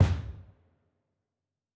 Special Click 27.wav